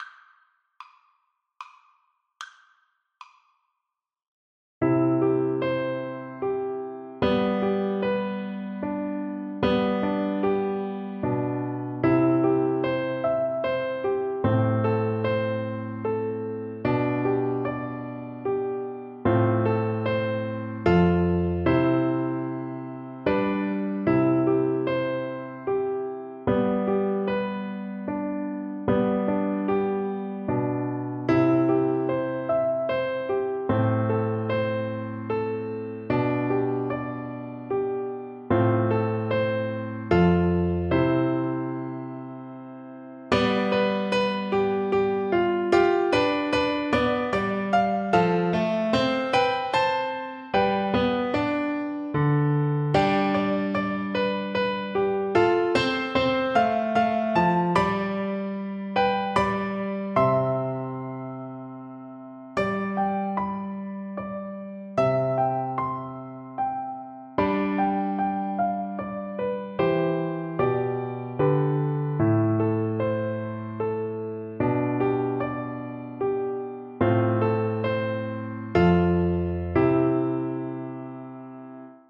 Traditional Scottish
3/4 (View more 3/4 Music)
G3-D5
Gently Rocking = c. 110